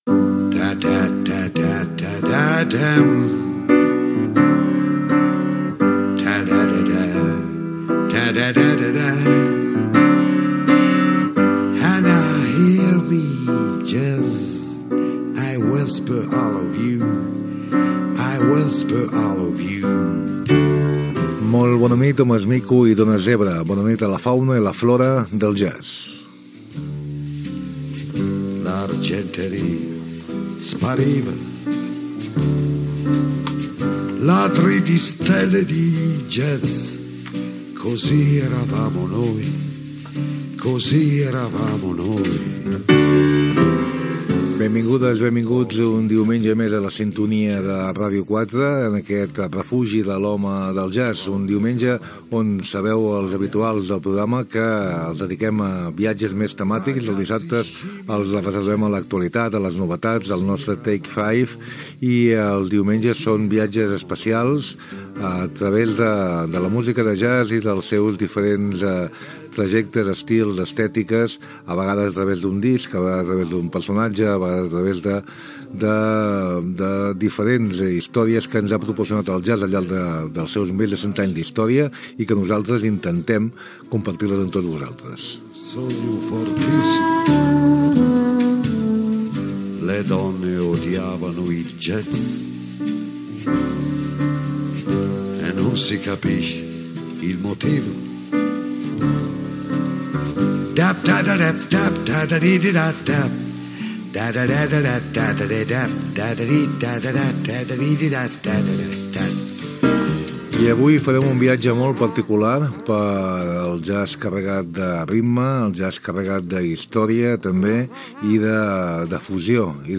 Sintonia del programa, presentació, entrevista
Musical